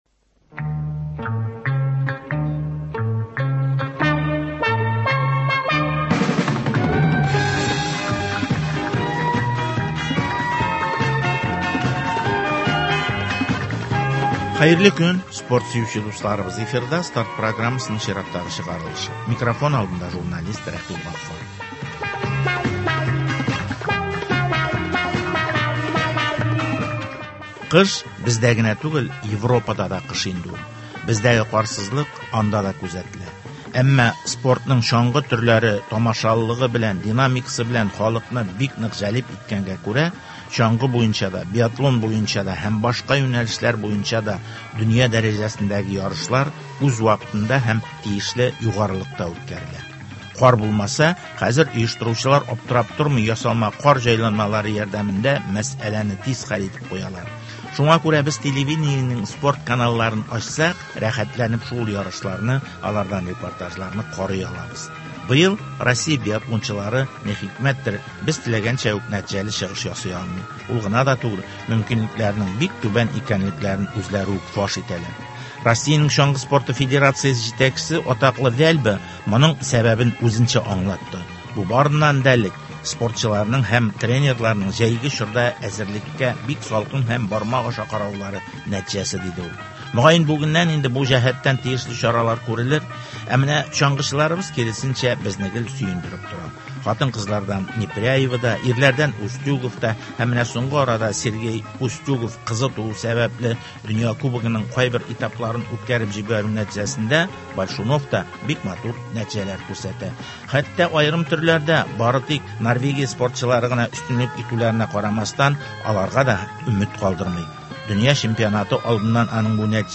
чаңгы спорты елына нәтиҗәләр ясау, безнең чаңгычыларның Россиядә һәм халыкара аренада ничек чыгыш ясаулары, Татарстанда төрнең үсеш мөмкинлекләре һәм перспективалары хакында әңгәмә.